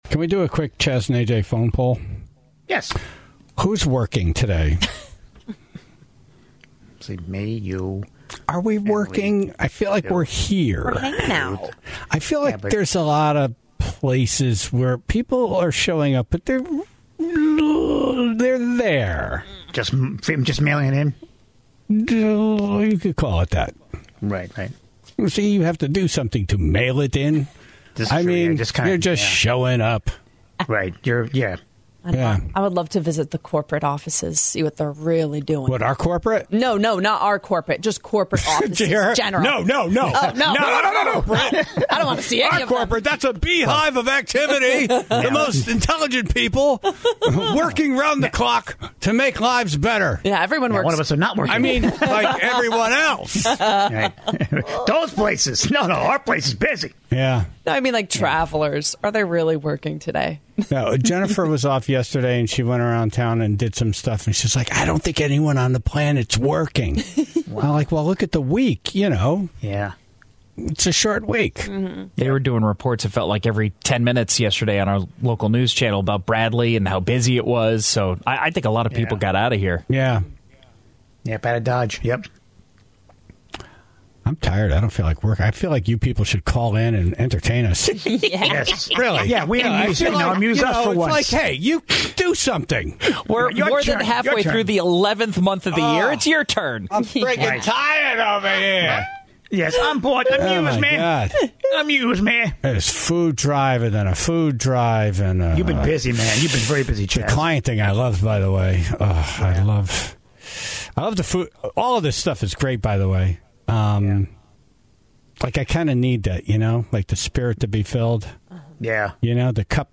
The Tribe then continued to call in about the people they are not thankful for this time of year, with a heavy emphasis on energy companies.